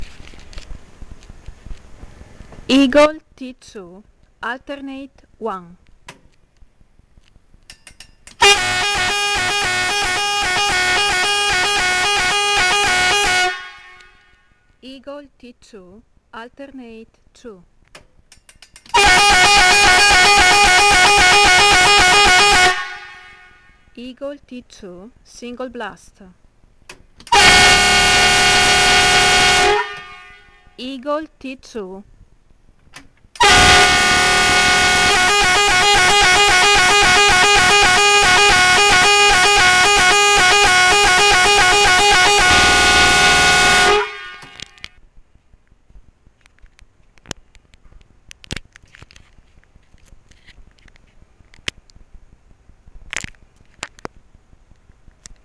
Fisa luchthoorn Eagle T2 24V 116dB 30W Middle Tone 402Hz Low Tone 360Hz